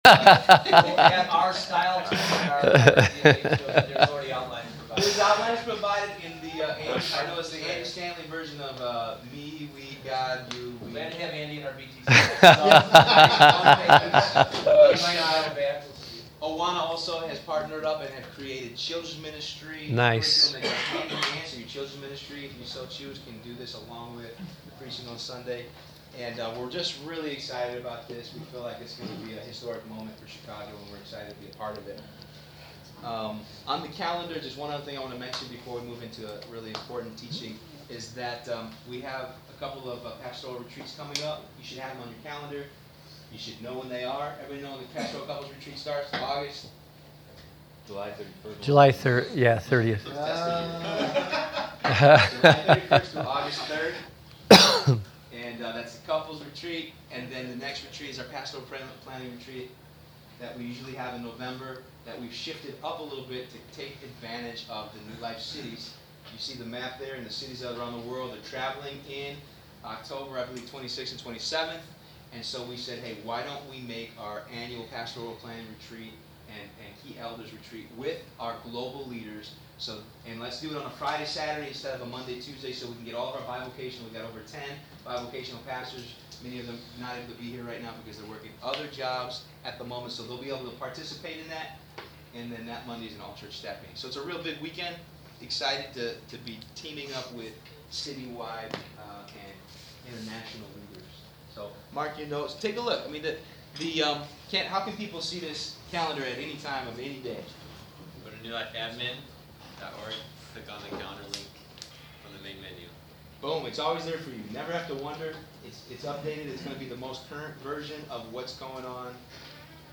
Download: Meeting Notes Teaching Audio